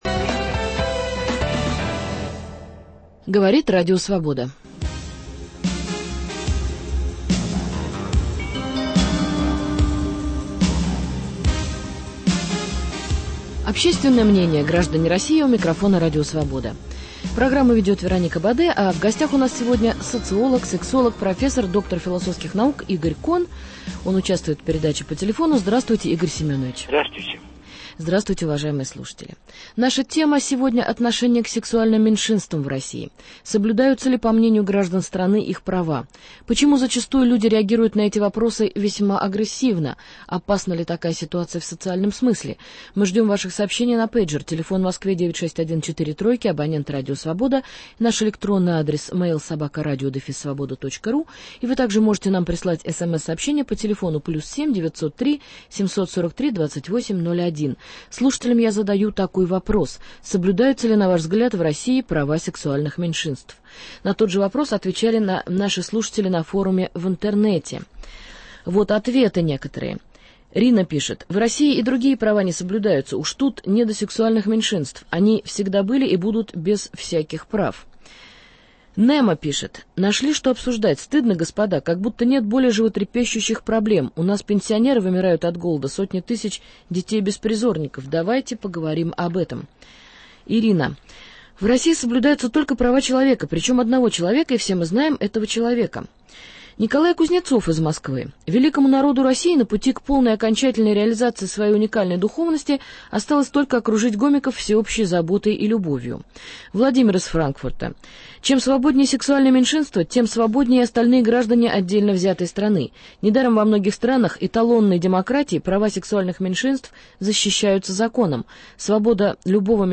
Соблюдаются ли в России права сексуальных меньшинств? Отношение граждан страны к сексуальным меньшинствам обсуждаем с профессором, доктором философских наук Игорем Коном.